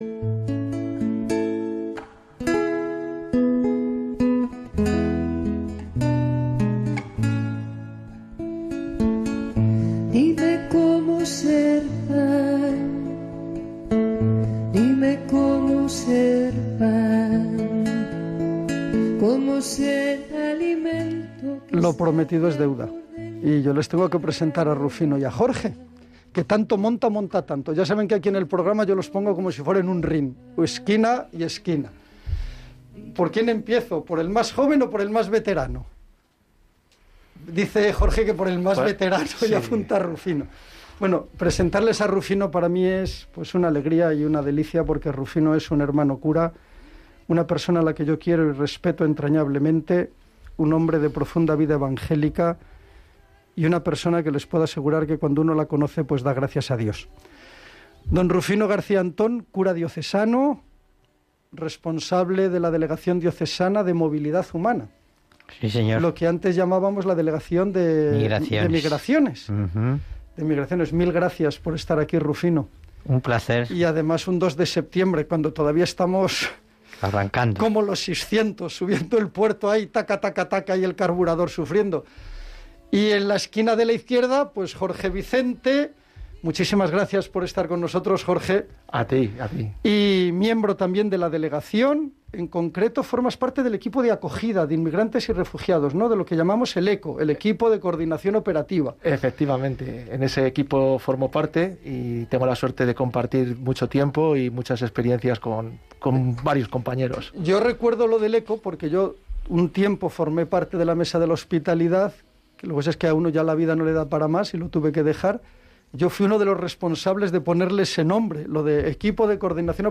Audio (podcast) del espacio «Iglesia Viva», dedicado a la Pastoral Social y, en esta emisión en Radio María del 2.9.22, centrado la Pastoral con Migrantes y Regugiados.